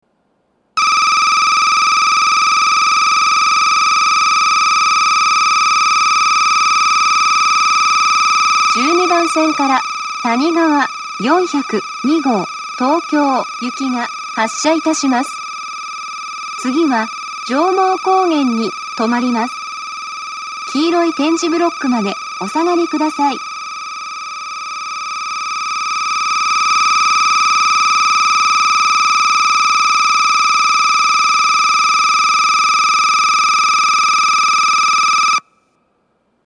２０２１年１０月１日にはCOSMOS連動の放送が更新され、HOYA製の合成音声による放送になっています。
１２番線発車ベル たにがわ４０２号東京行の放送です。